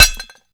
grenade_hit_metal_01.WAV